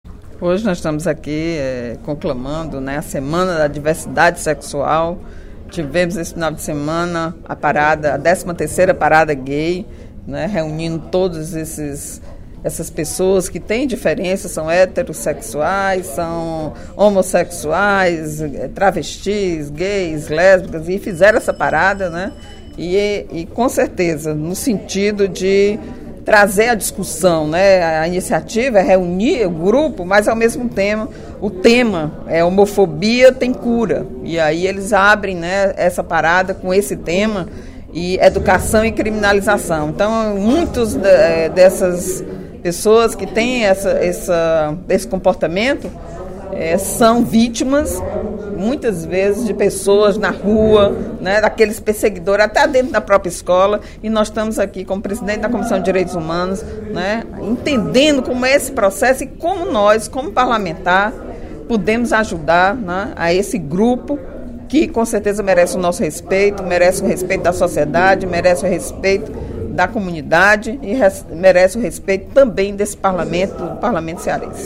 A deputada Eliane Novais (PSB) destacou, nesta terça-feira (26/06) em plenário, a realização no último domingo (24), em Fortaleza, da XIII Parada pela Diversidade Sexual do Ceará.